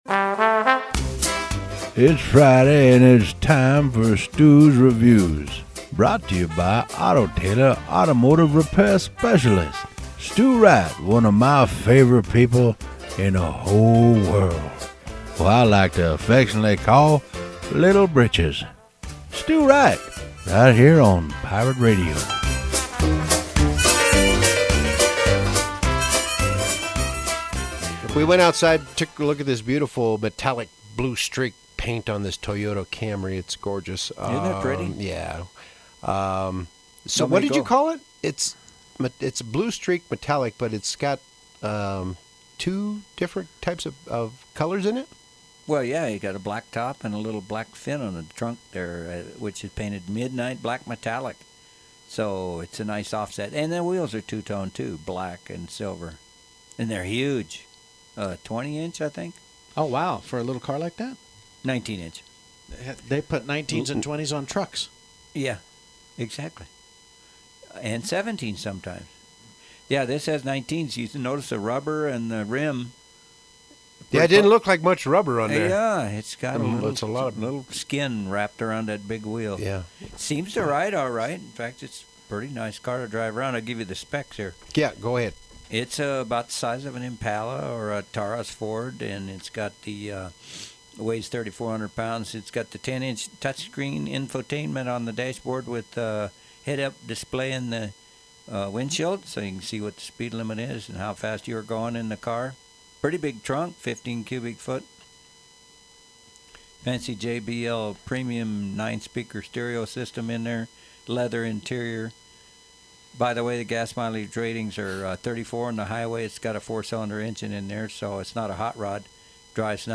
Radio review at Pirate radio